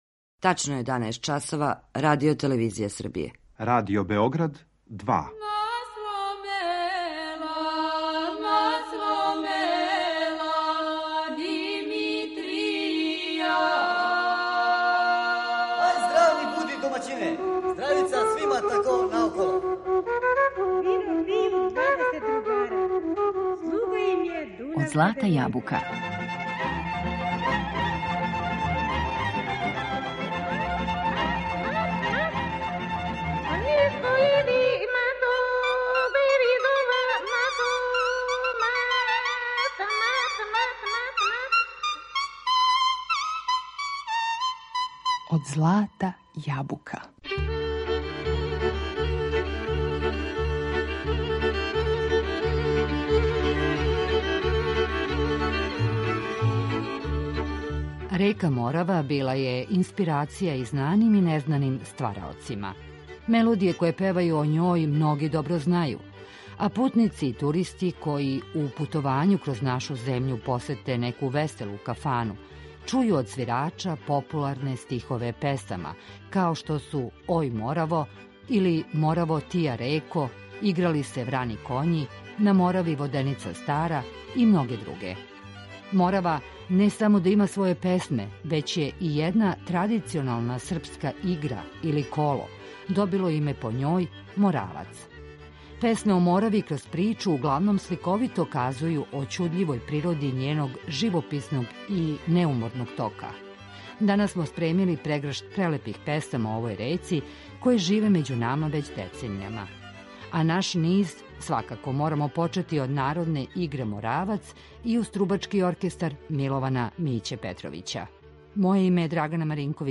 На таласима ове реке, уз проверену народну музику, пловићемо у данашњој емисији Од злата јабука .